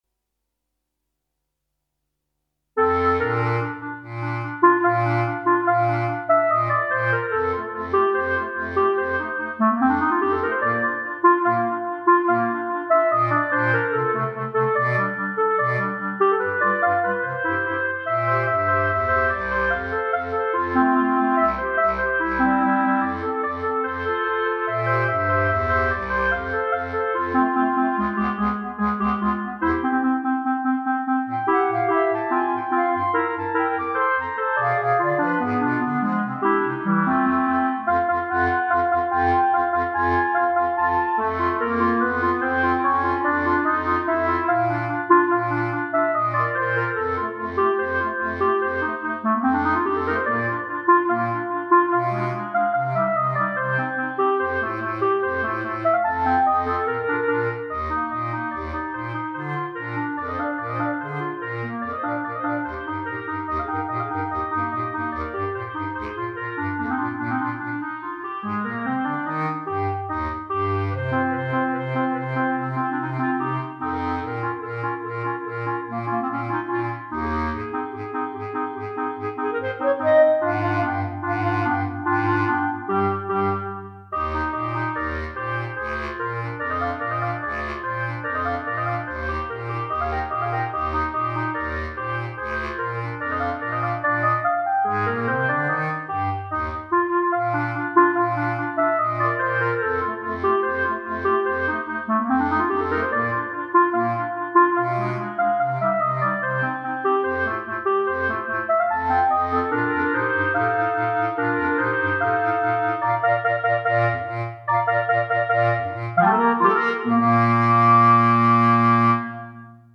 Instrumentation:3 Clarinet, Bass Cl.
as a Clarinet Quartet.